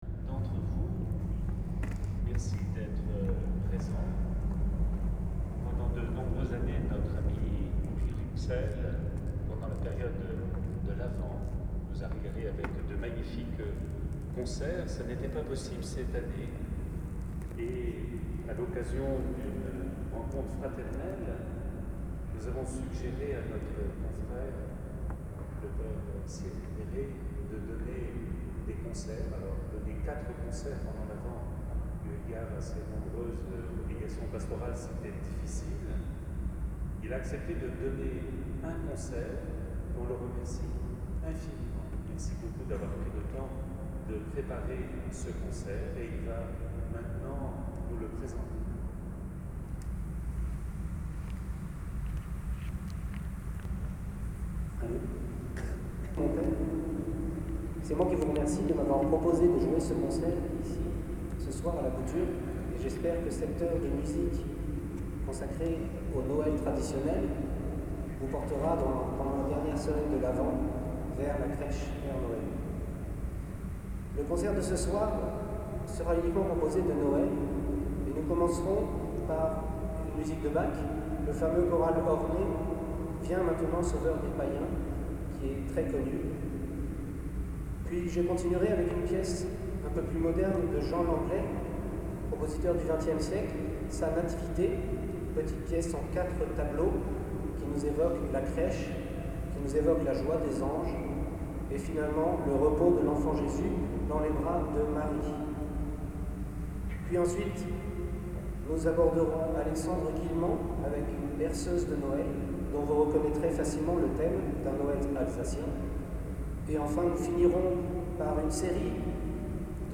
Concert d’Orgue